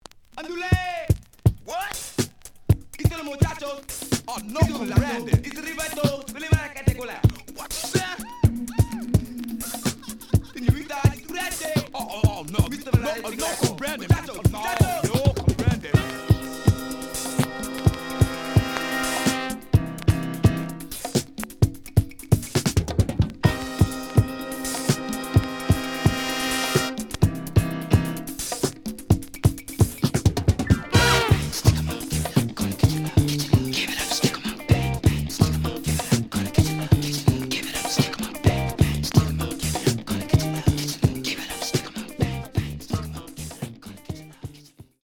The audio sample is recorded from the actual item.
●Genre: Funk, 70's Funk
●Record Grading: VG~VG+ (傷はあるが、プレイはおおむね良好。Plays good.)